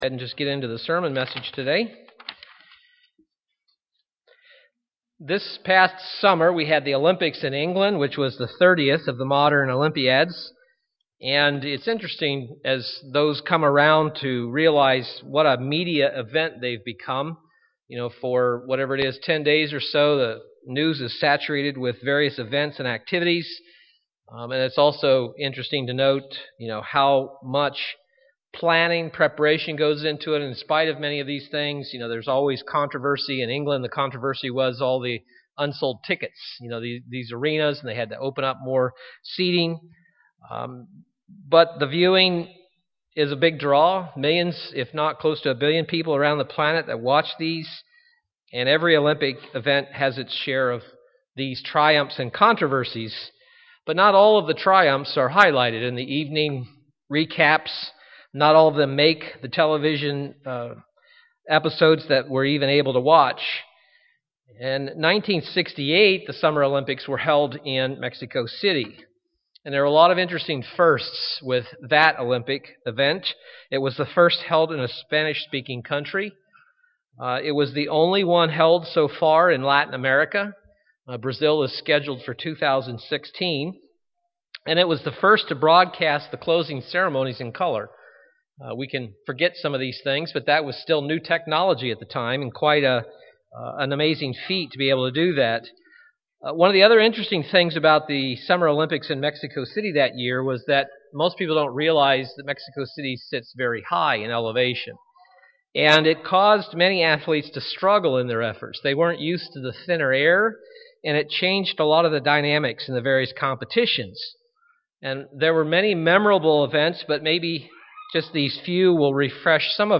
Given in Milwaukee, WI
Print The spiritual race set before us is not easy, but it is one we can finish if we put forth effort UCG Sermon Studying the bible?